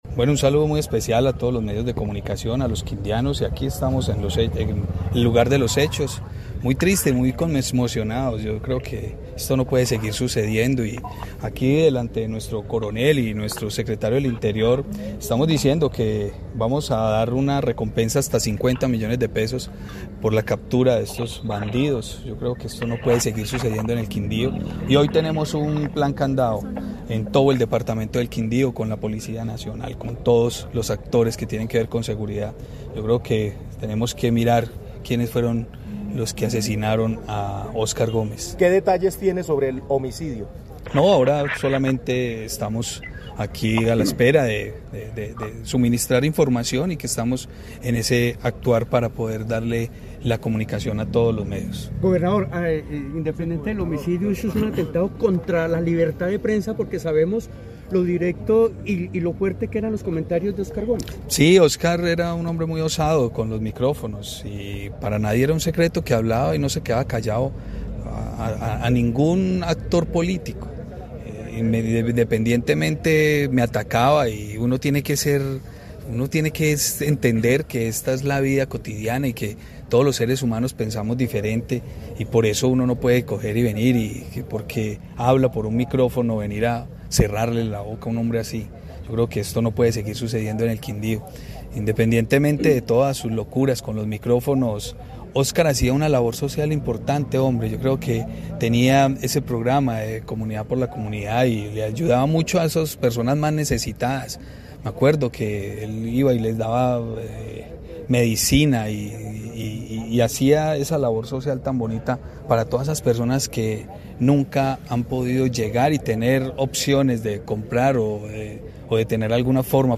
Juan Miguel Galvis, Gobernador del Quindío